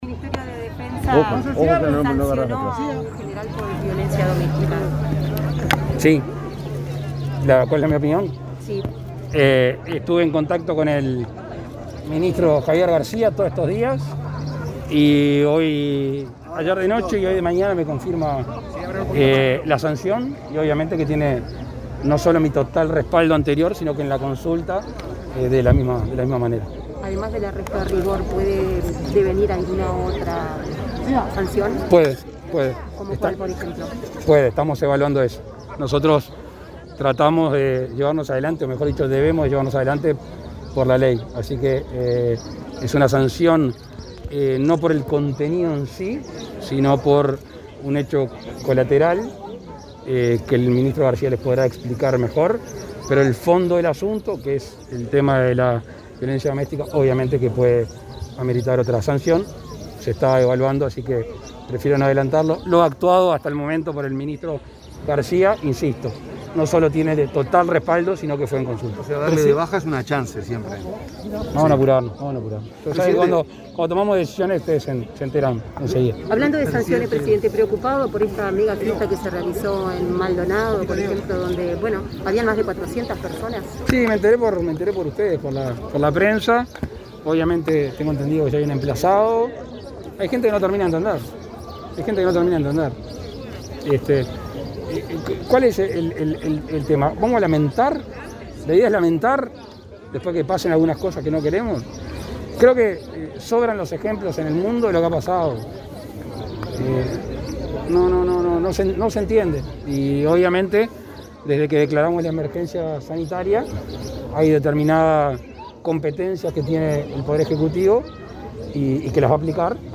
“Hay gente que no termina de entender”, dijo el presidente Lacalle Pou en Sarandí Grande, acerca de la realización de una megafiesta no autorizada en Maldonado, dado que no se cumplieron los protocolos sanitarios previstos por la pandemia de COVID-19. El mandatario recordó que sobran los ejemplos en el mundo de cuáles son las consecuencias de esas actitudes y anunció que el Gobierno tiene potestades para aplicar sanciones.